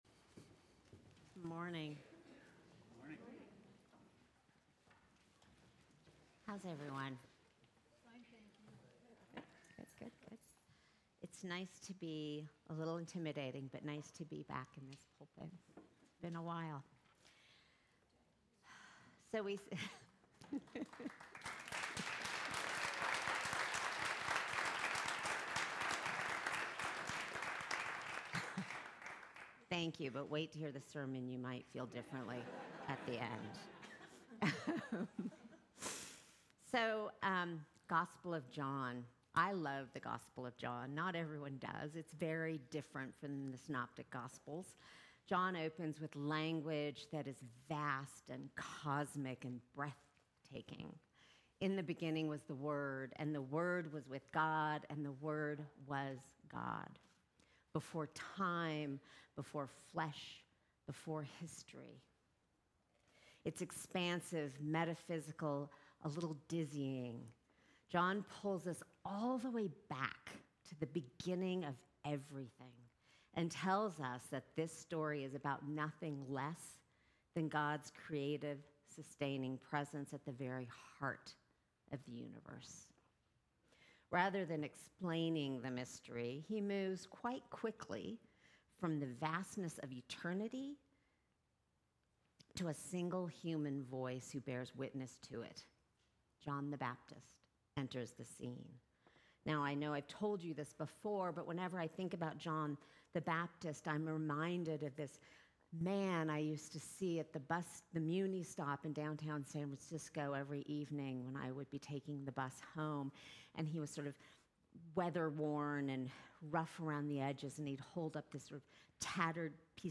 Sermons from St. John's Episcopal Church